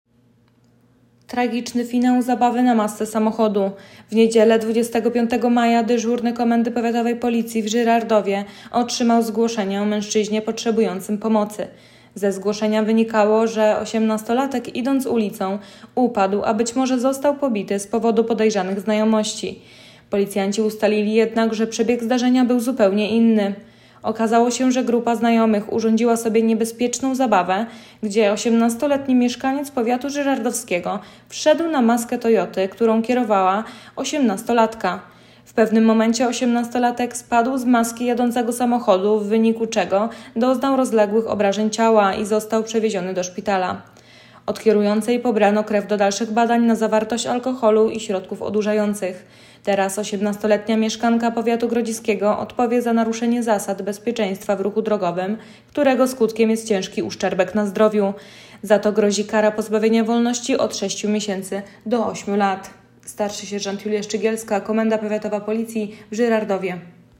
Nagranie audio Wypowiedź st. sierż.